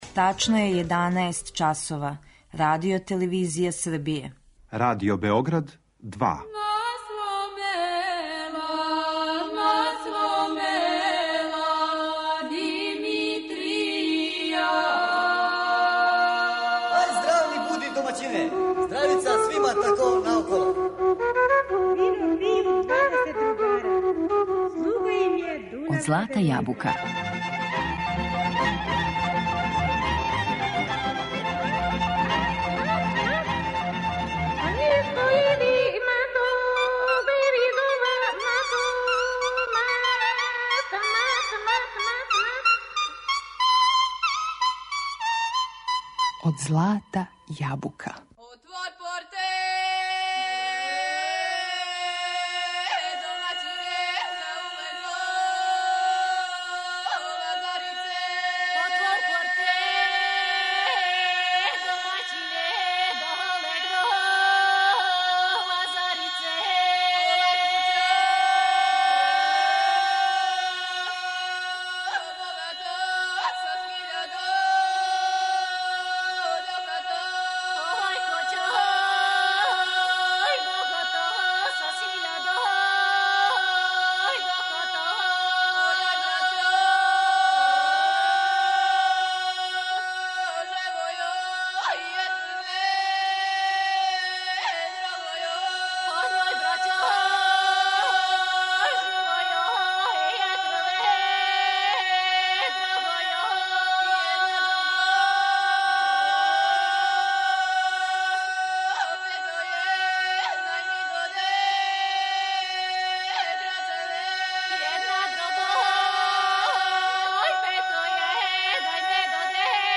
Женске певачке групе
Како се данас жене баве извођењем, неговањем и обогаћивањем српске вокалне традиције? У емисији ће бити представљене професионалне изворне групе, али и оне које делују у оквиру културно-уметничких друштава.